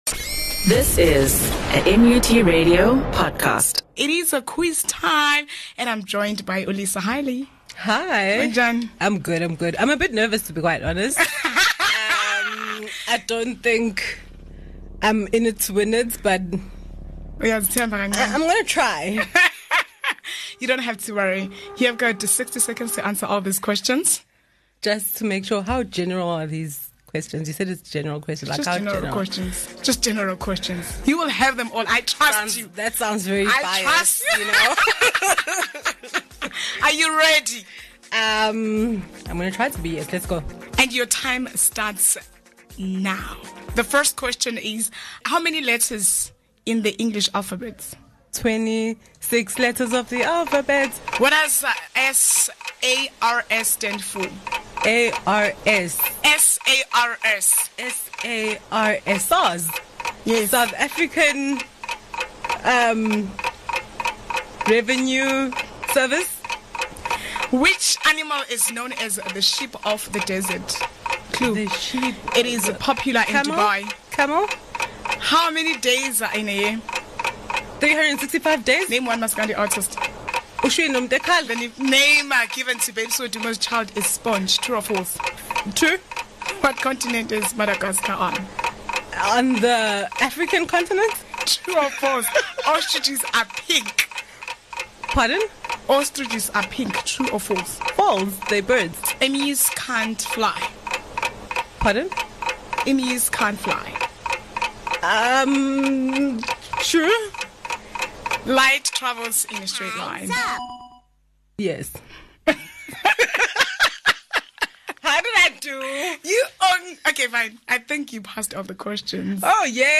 A one minute quiz, (sixty second - ten questions) is a knowledge-testing activity where a guest answers ten questions in a minute.